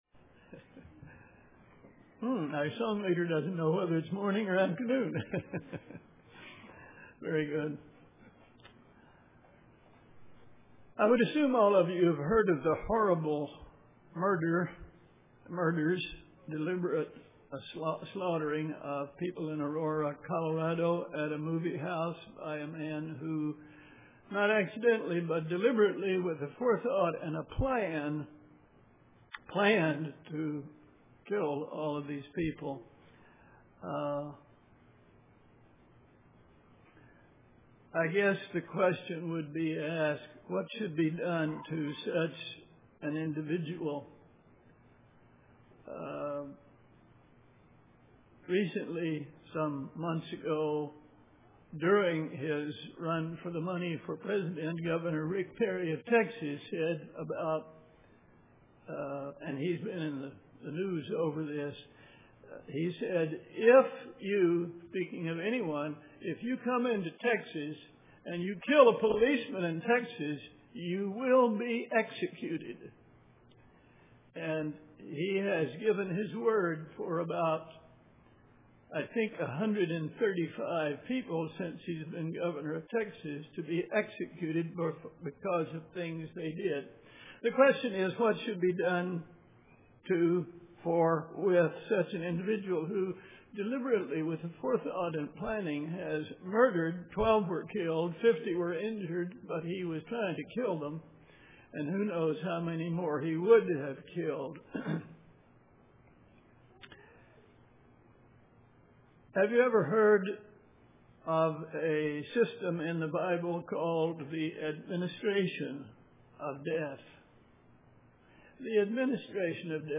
Print Living by God's law and spirit.The Administration of Death UCG Sermon Studying the bible?